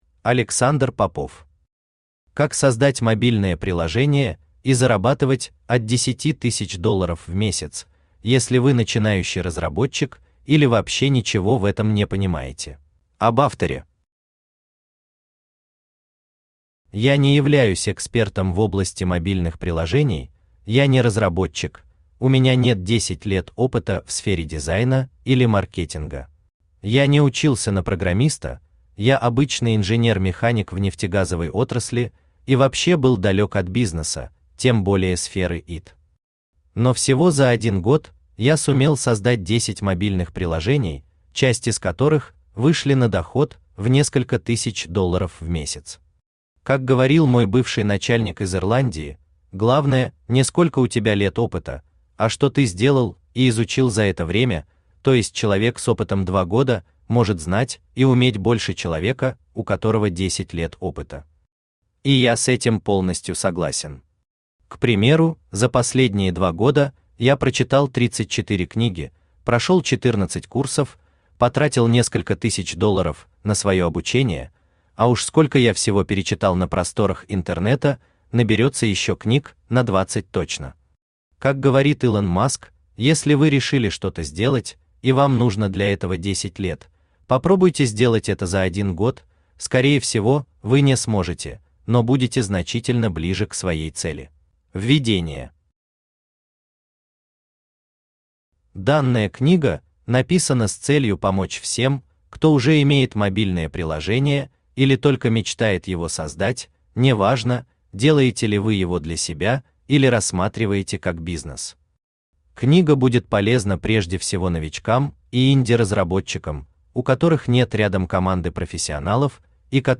Аудиокнига Как создать мобильное приложение и зарабатывать от 10000$ в месяц, если вы начинающий разработчик или вообще ничего в этом не понимаете | Библиотека аудиокниг
Aудиокнига Как создать мобильное приложение и зарабатывать от 10000$ в месяц, если вы начинающий разработчик или вообще ничего в этом не понимаете Автор Александр Евгеньевич Попов Читает аудиокнигу Авточтец ЛитРес.